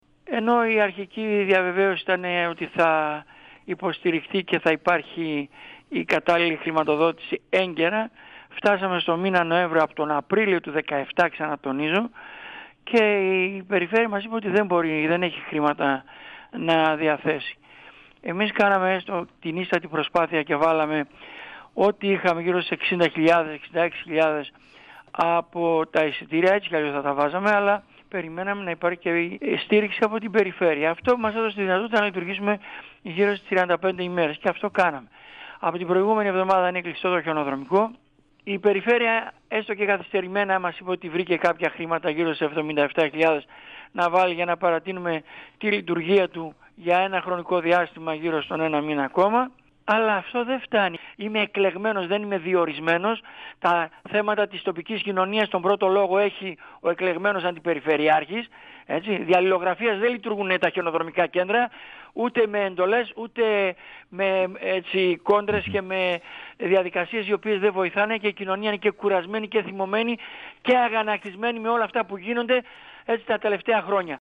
Ο αντιπεριφερειάρχης Δράμας, Αργύρης Πατακάκης, στον 102FM του Ρ.Σ.Μ. της ΕΡΤ3
Συνέντευξη στην εκπομπή «Μάθε τι παίζει»